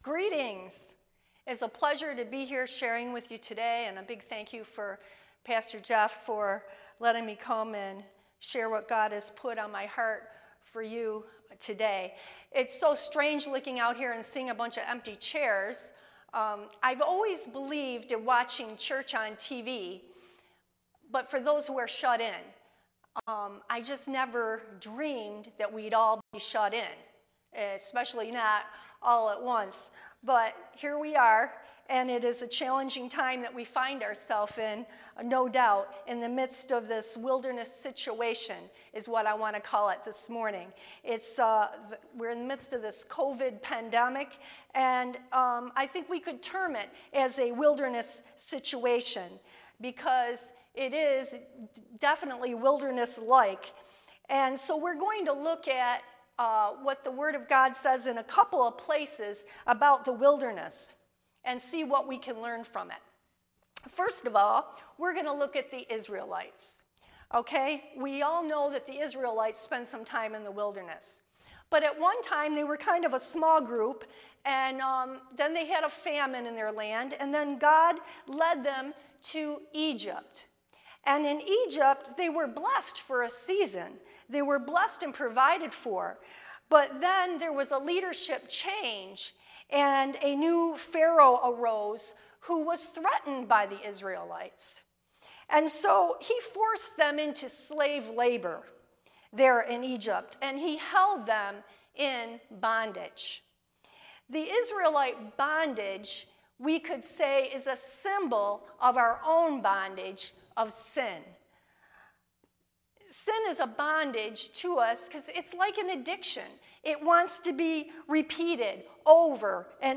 This week we hear from a guest speaker